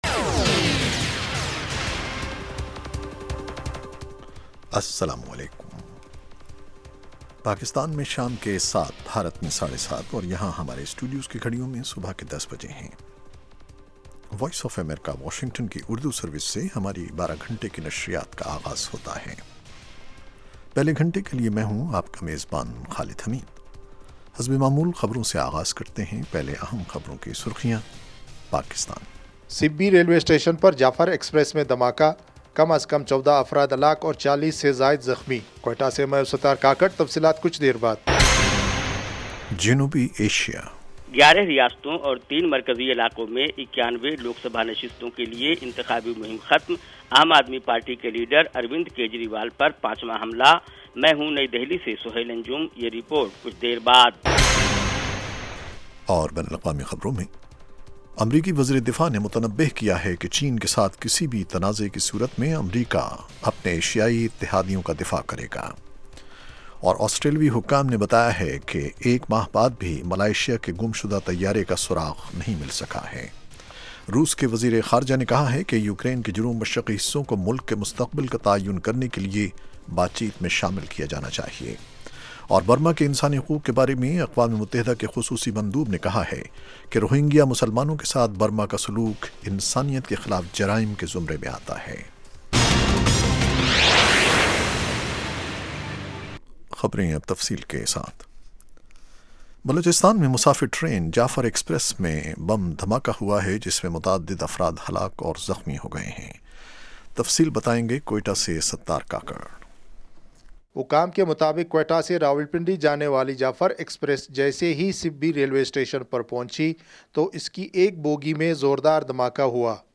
7:00PM اردو نیوز شو اس ایک گھنٹے میں دن بھر کی اہم خبریں اور پاکستان اور بھارت سے ہمارے نمائندوں کی روپورٹیں پیش کی جاتی ہیں۔ اس کے علاوہ انٹرویو، صحت، ادب و فن، کھیل، سائنس اور ٹیکنالوجی اور دوسرے موضوعات کا احاطہ کیا جاتا ہے۔